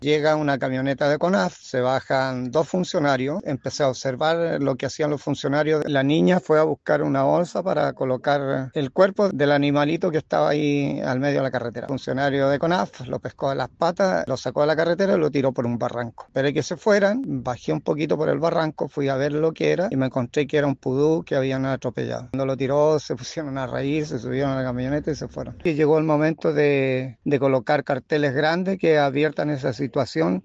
En este sentido, un auditor de La Radio, quien se encontraba en la carretera cerca del parque, acusó este hecho al ver las acciones de los guardaparques de Conaf.